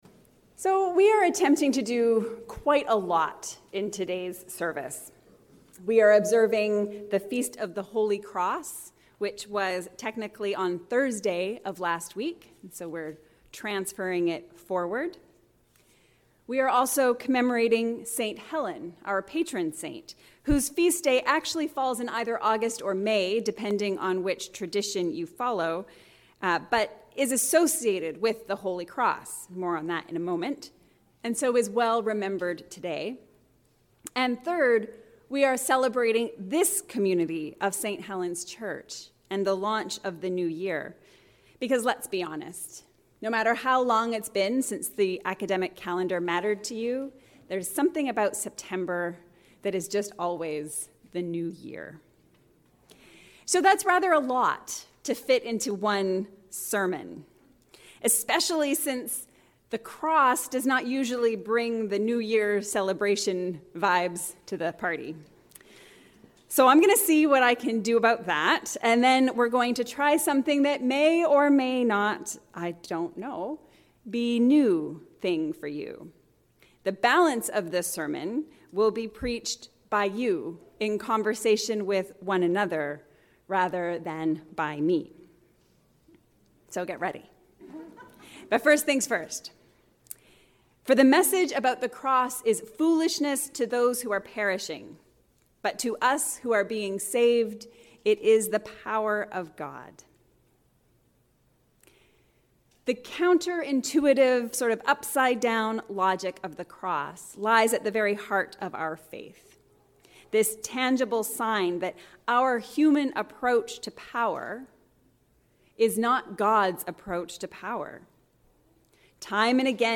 This sermon included a time of conversation as folks shared their stories of people who helped them see God and God’s way with new clarity.